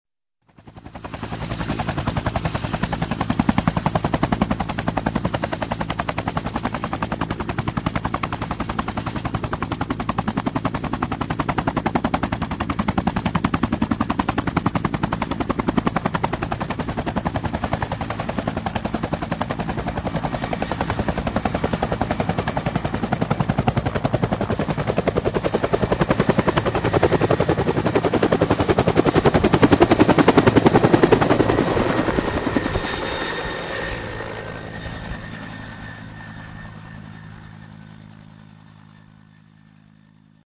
直升机飞走了: